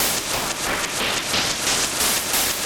Index of /musicradar/rhythmic-inspiration-samples/90bpm
RI_ArpegiFex_90-03.wav